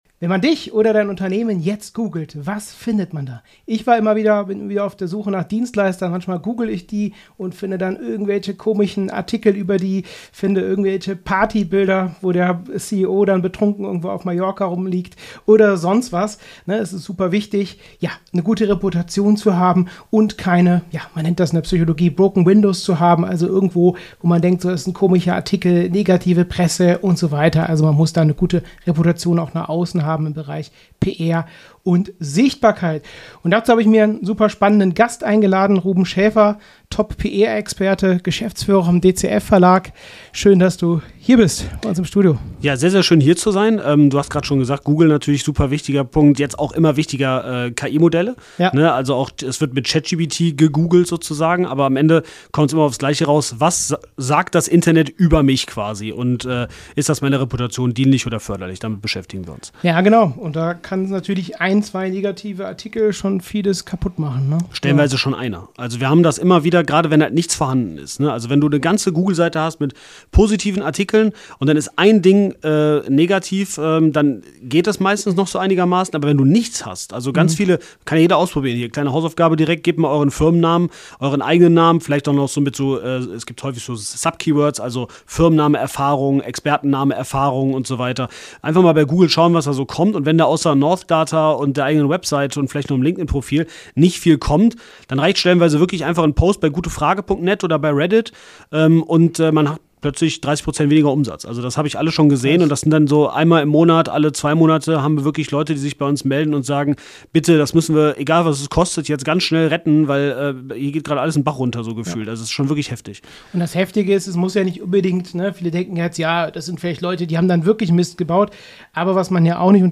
Reputation retten: Ein einziger Satz kann alles ändern - Interview